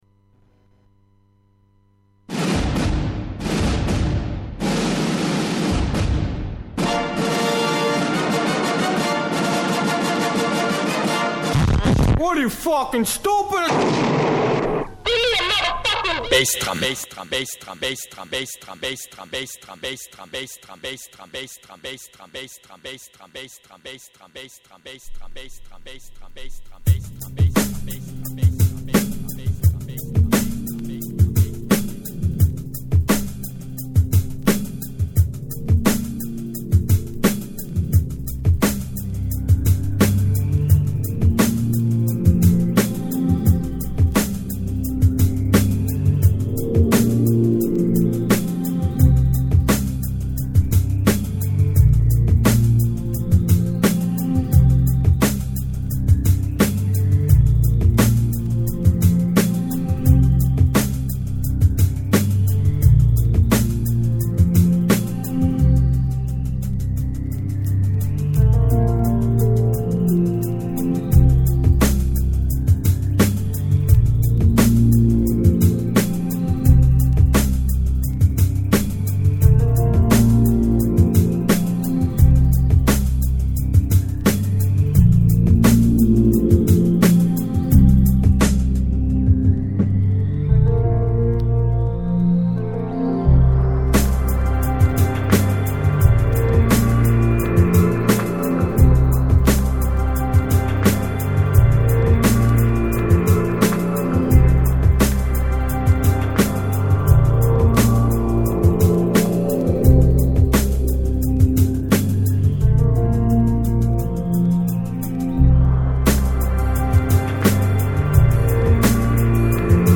Para cerrar la temporada 2007/08, apostamos por nuestra fórmula de repaso de las novedades discográficas y de los temas míticos de la historia del Hardcore. Comentamos fiestas y noticias y te damos algunas pistas para este verano.